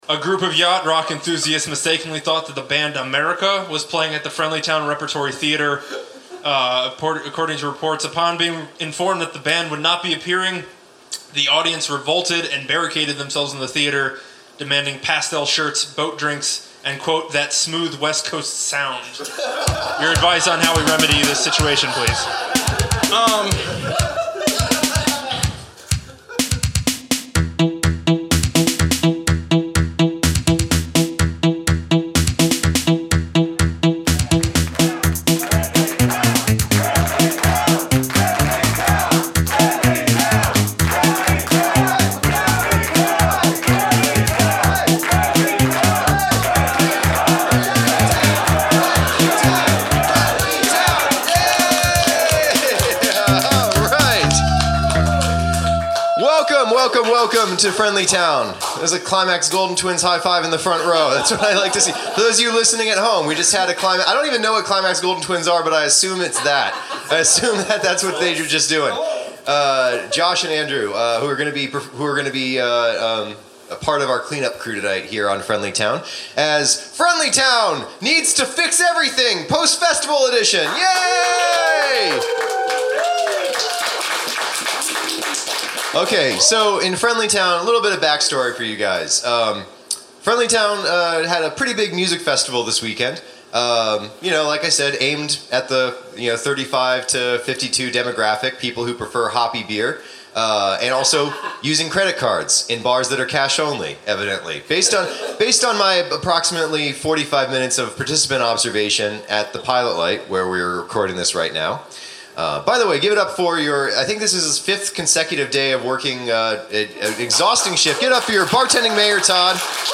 Recorded Live at the Pilot Light on March 23, 2018 Subscribe on iTunes here!